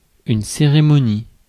Ääntäminen
IPA : /ˈsɛrəmoʊnɪ/